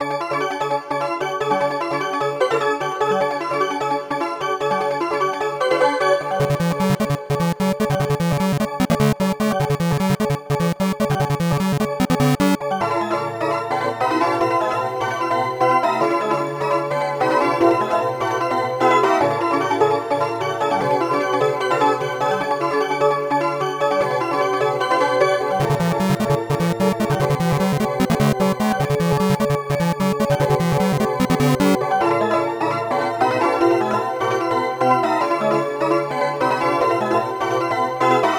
A fast paced small piece under work